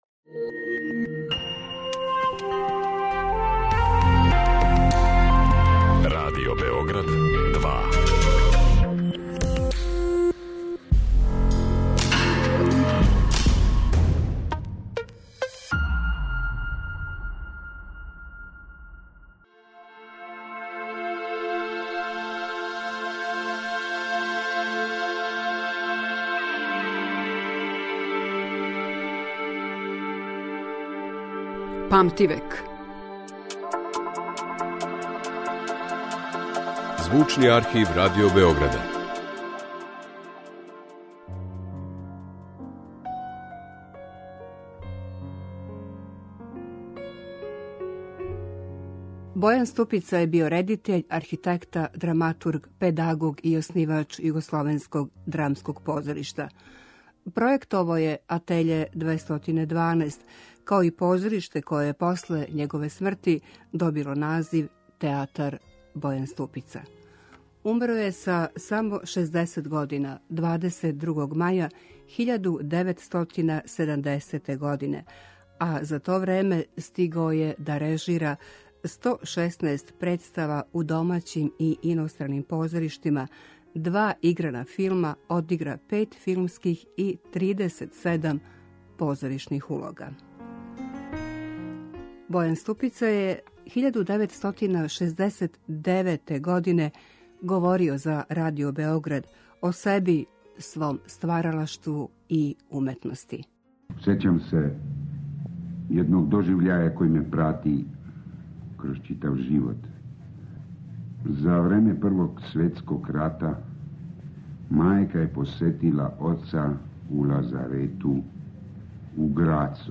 У данашњој емисији слушамо како је Бојан Ступица говорио о себи, свом стваралаштву и уметности.